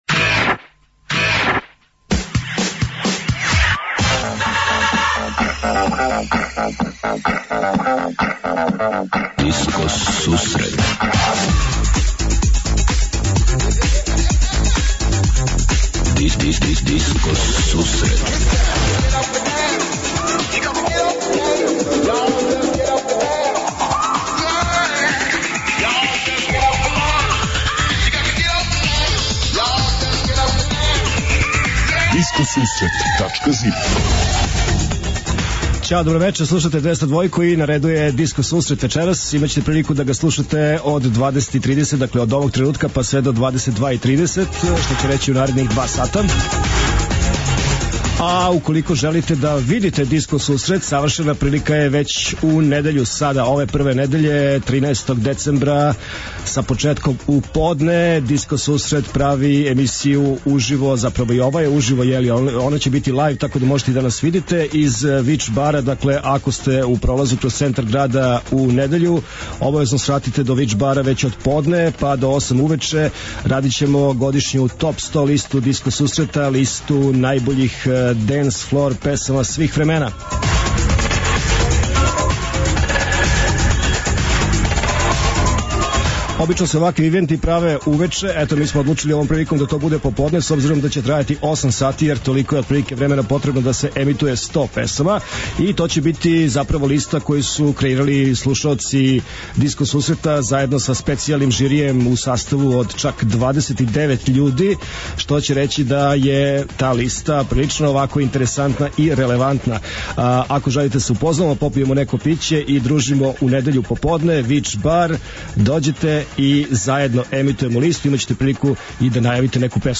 20:30 Диско Сусрет Топ 40 - Топ листа 40 актуелних синглова, пажљиво одабраних за оне који воле диско музику. 21:30 Винил Зона - Слушаоци, пријатељи и уредници Диско Сусрета пуштају музику са грамофонских плоча.
преузми : 28.48 MB Discoteca+ Autor: Београд 202 Discoteca+ је емисија посвећена најновијој и оригиналној диско музици у широком смислу, укључујући све стилске утицаје других музичких праваца - фанк, соул, РнБ, итало-диско, денс, поп.